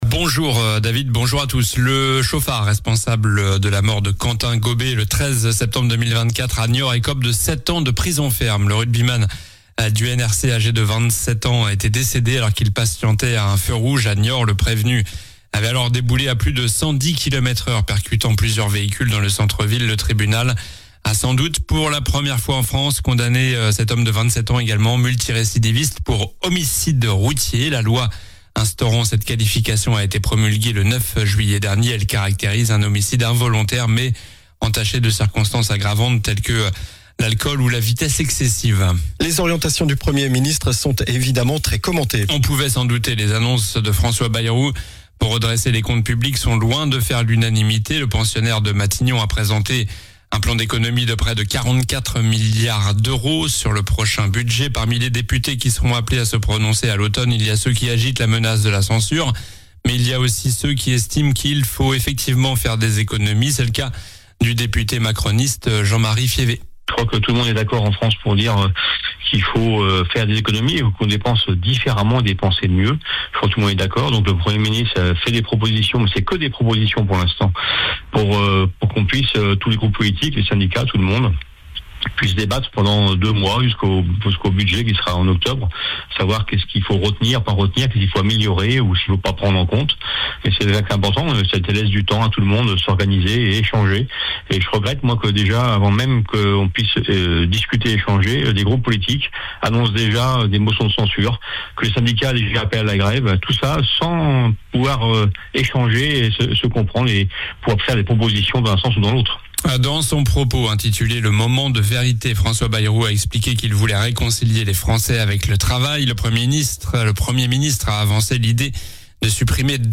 Journal du jeudi 17 juillet (matin)